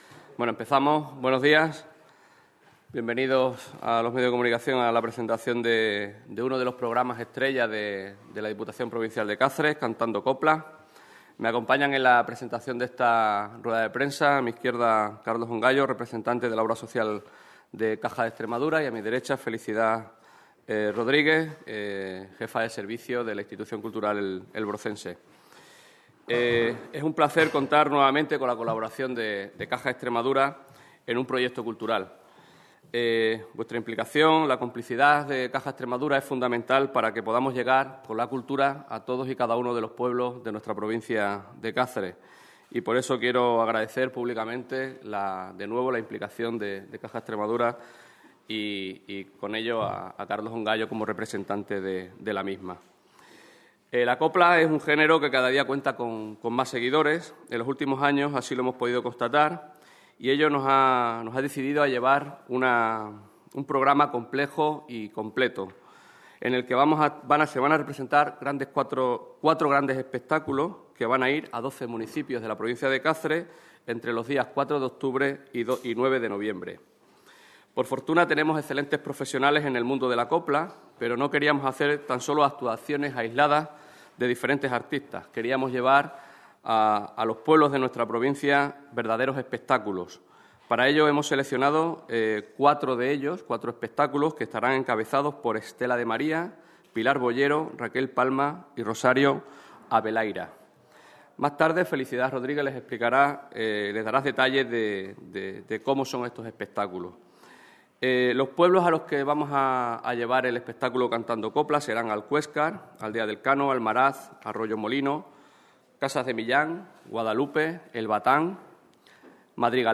CORTES DE VOZ
ha presentado este miércoles en rueda de prensa el programa de conciertos ‘Cantando Copla’.